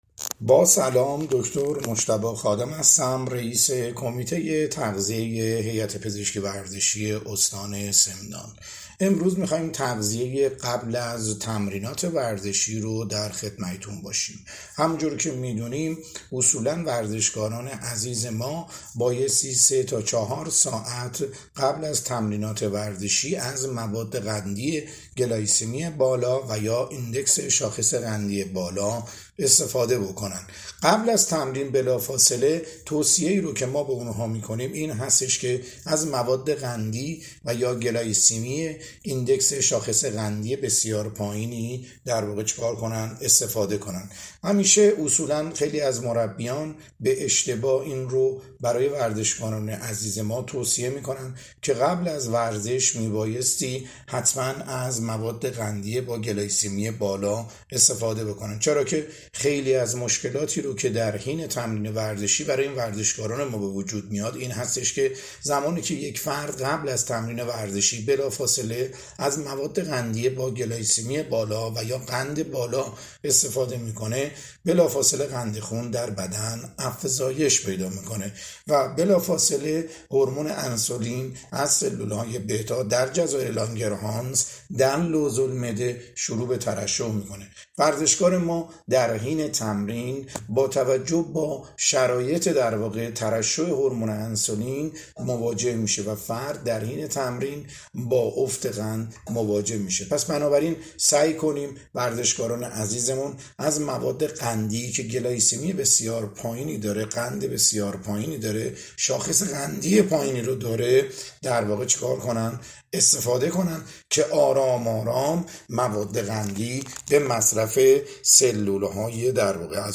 صوت آموزشی/